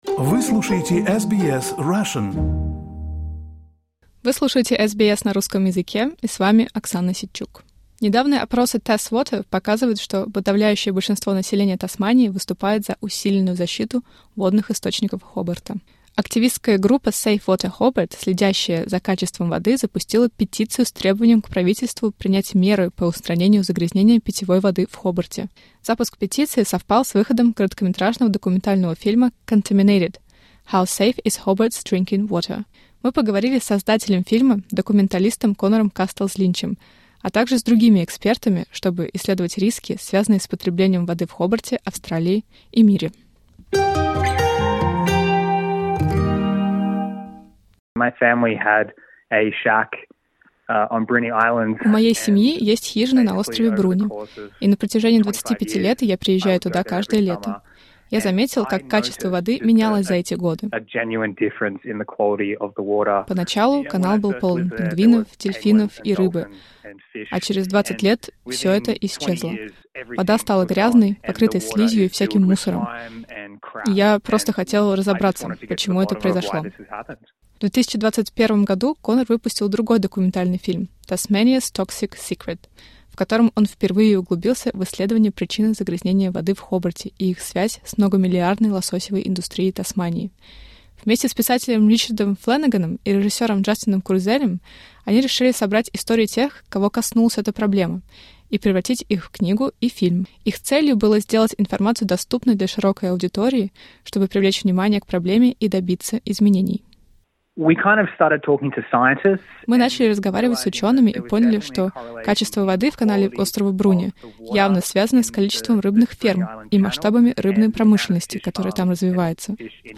The documentary 'Contaminated' and the water watchdog Safe Water Hobart raise concerns about the safety of drinking water in Tasmania, suggesting a possible link between the toxin BMAA and neurodegenerative diseases. Activists, experts, and government regulators weigh in on the effects of industrial pollution and the current regulations around BMAA in Australia.